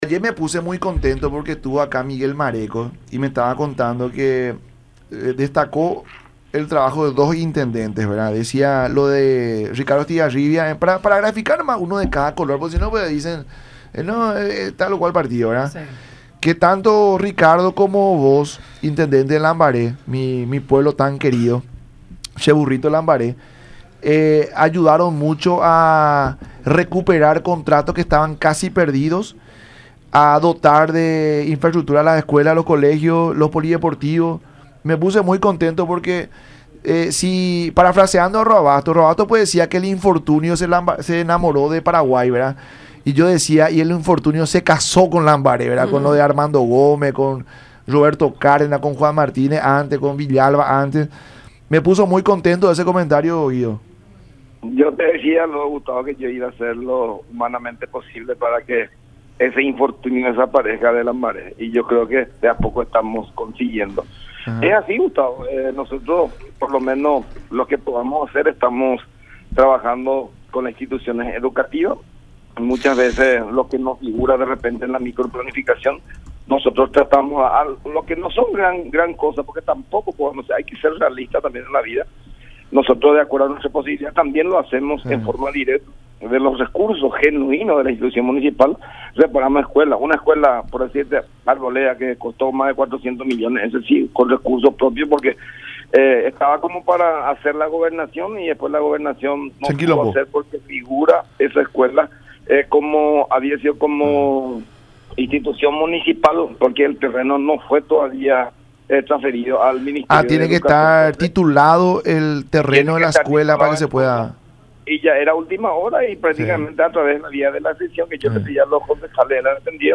Guido González, intendente de Lambaré.
“Volvimos a nuestra casa, volvimos a Honor Colorado. Hasta el 2017 estuvimos luego ahí y salimos porque un amigo era el candidato a la vicepresidencia Hugo Velázquez”, dijo González en conversación con La Mañana De Unión a través de Unión TV y radio La Unión.